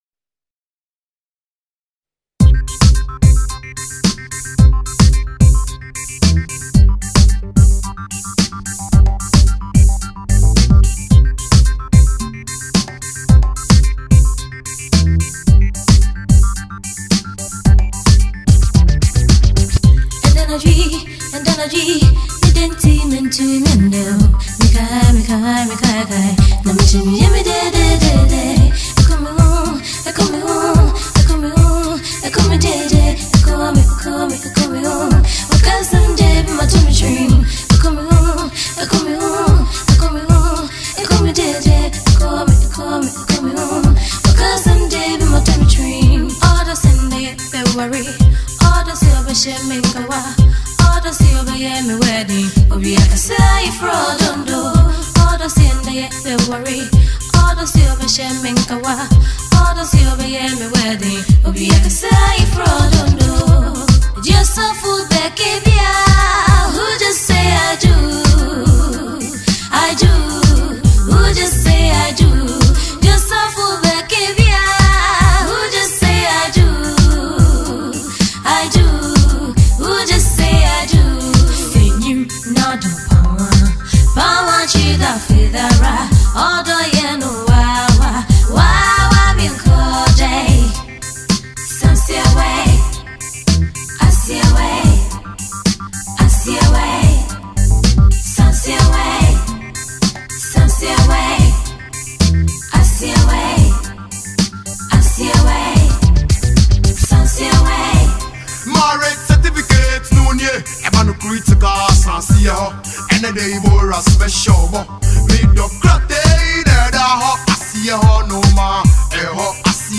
New Highlife Music